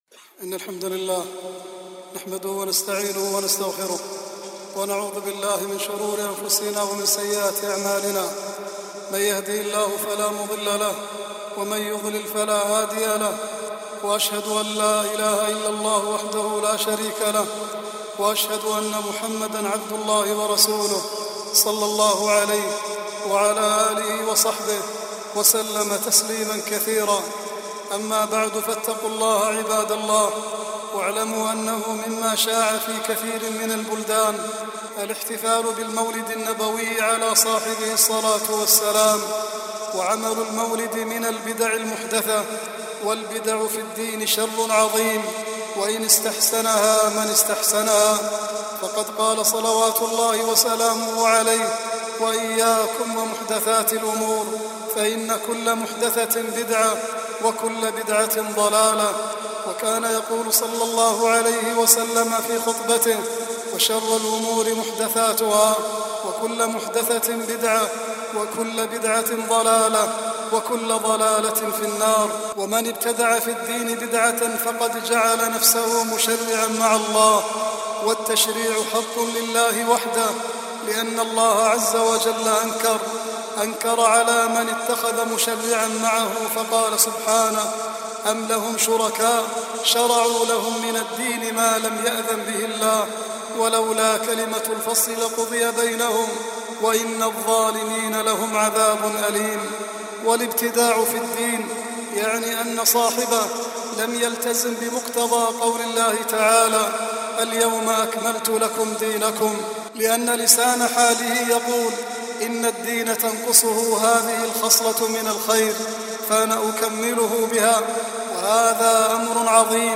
12-9-16-khutbah-.mp3